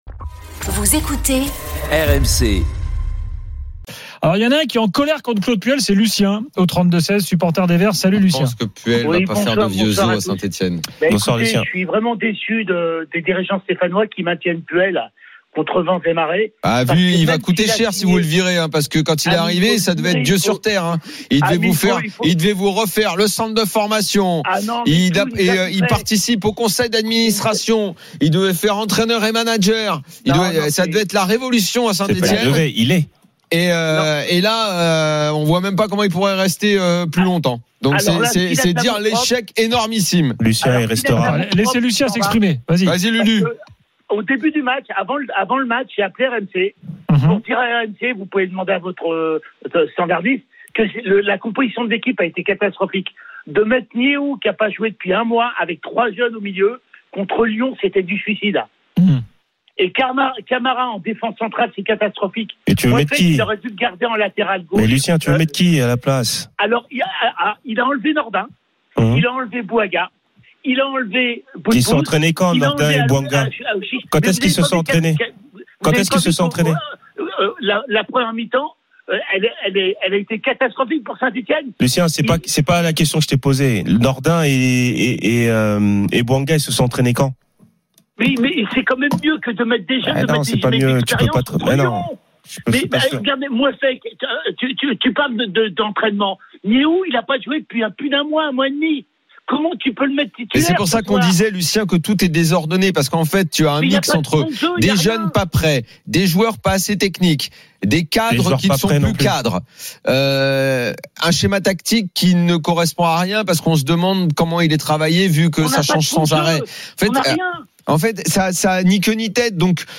supporter stéphanois, dépité après le derby